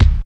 27.10 KICK.wav